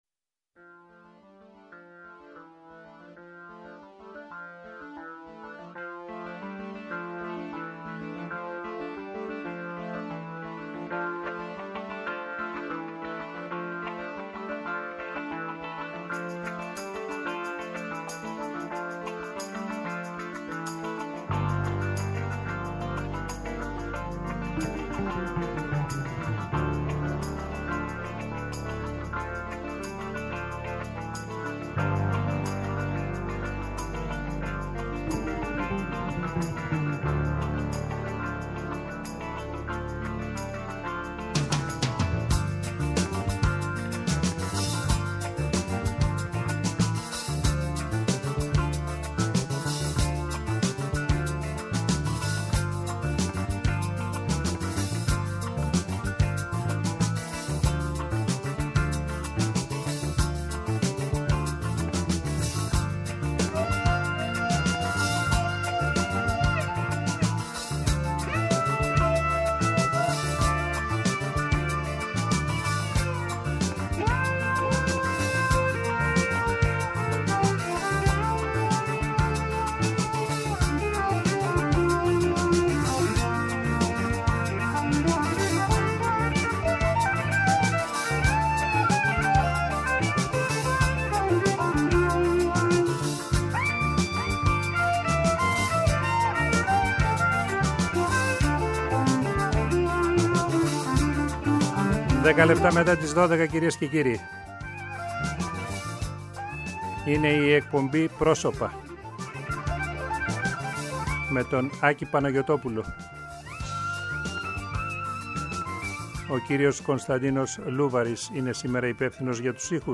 Κάθε συνέντευξη διαρκεί μία ώρα, χρόνος αρκετός για εκείνους που έχουν κάτι καινούργιο να σας πουν